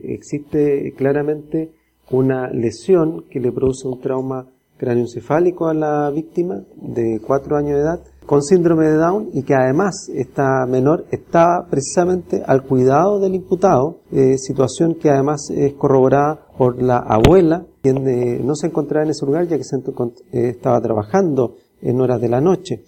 El fiscal adjunto de Yungay Mario Lobos, explicó al término de la audiencia que se extendió por más de tres horas, que todos los antecedentes que se expusieron apuntan a que el hombre no acudió a tiempo ni a Carabineros ni a un centro asistencial para dar cuenta de lo acontecido.